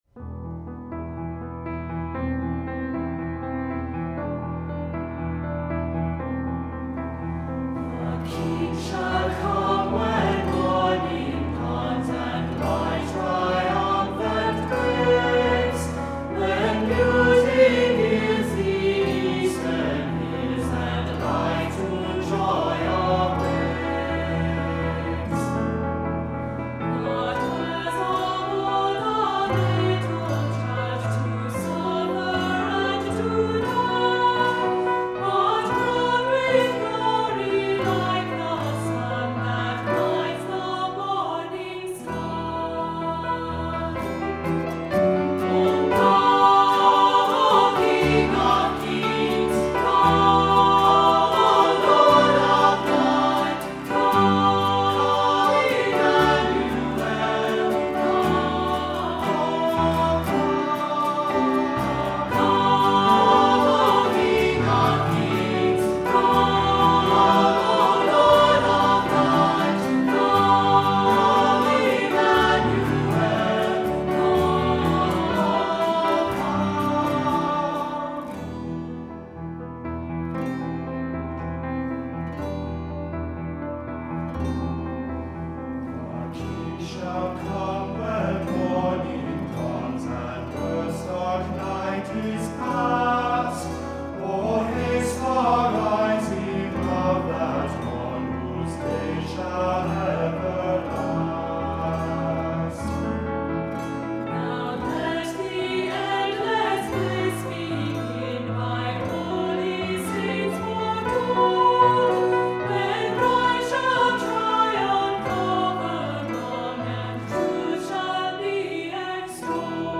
Voicing: SAT; Assembly